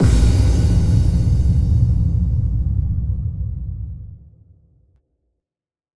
Impact 14.wav